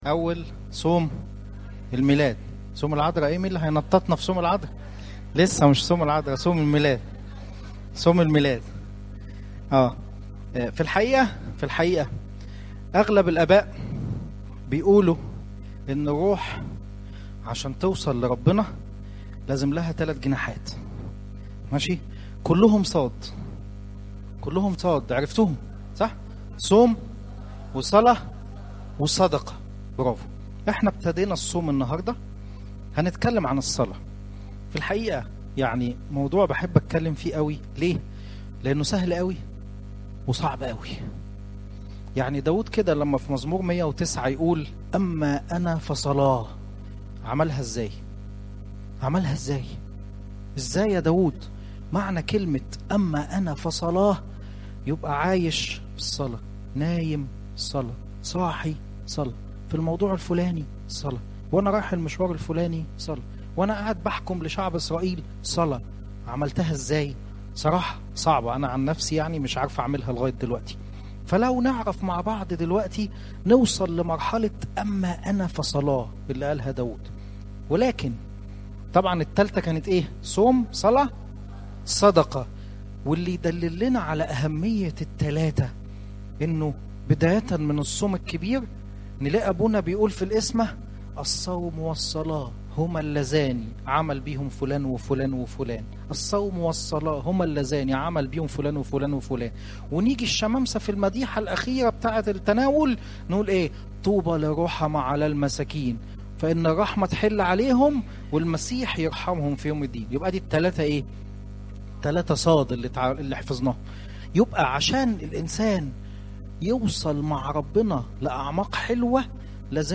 StMaryZTN Sermons